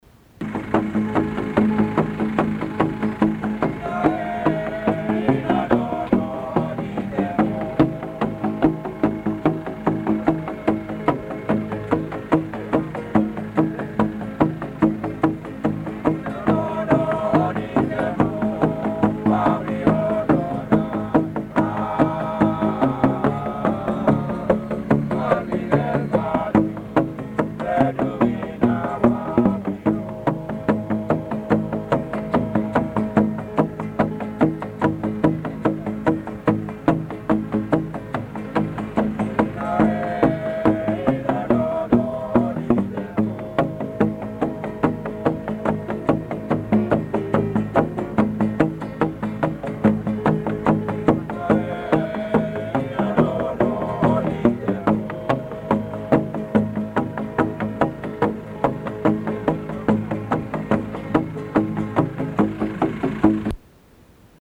I captured some of their music on my walkman-like device, including my talking to them in my attempt at Tok Pisin (the pidgin English spoken in New Guinea.)
men playing music on bamboo pipes with thongs (flip-flops) at Goroka Highlands show, Papua New Guinea, 1984 men playing music on bamboo pipes with thongs (flip-flops) at Goroka Highlands show, Papua New Guinea, 1984